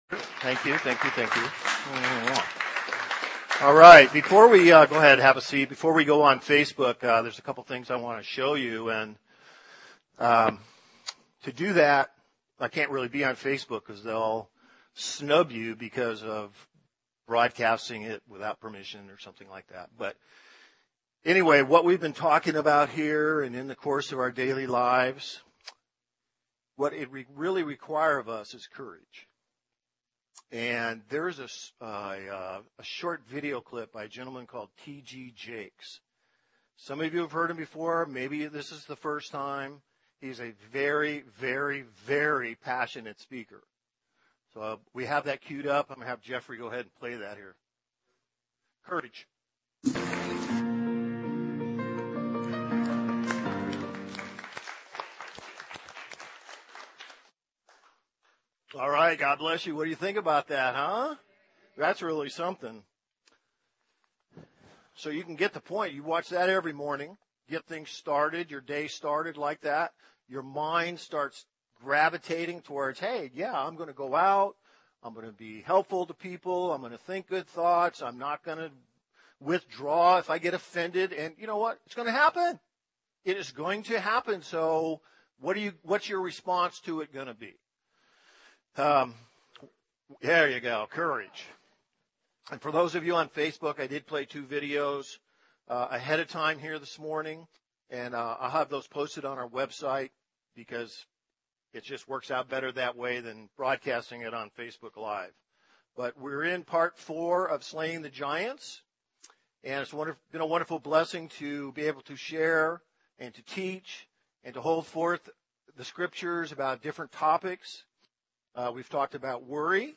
Details Series: 2020 - Walking in Victory Date: Sunday, 02 February 2020 Hits: 965 Scripture: Philippians 2:3-5; Romans 12:2 Play the sermon Download Audio ( 8.37 MB )